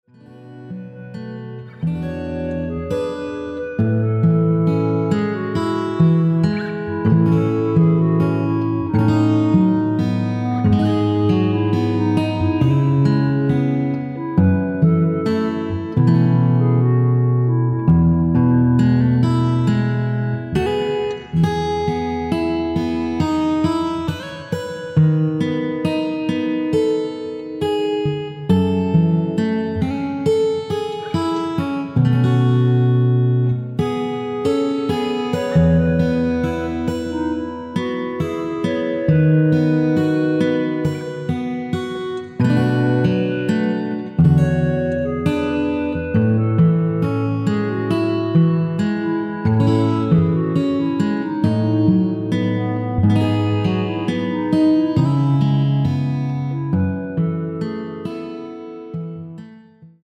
멜로디 포함된 MR입니다.
앞부분 “니가 어느날 ~ 오늘로 마지막이구나” 까지 없으며 간주도 4마디로 편곡 하였습니다.
멜로디 MR이라고 합니다.
앞부분30초, 뒷부분30초씩 편집해서 올려 드리고 있습니다.
중간에 음이 끈어지고 다시 나오는 이유는